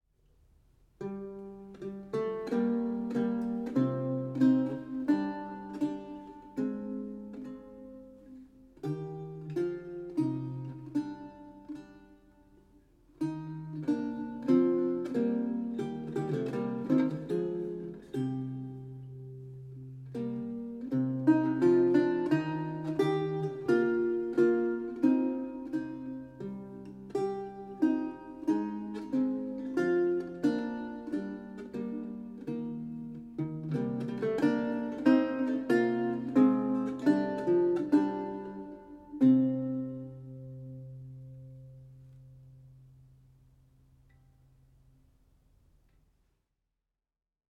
Audio recording of a lute piece
a 16th century lute music piece originally notated in lute tablature